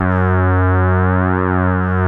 OSCAR F#2 5.wav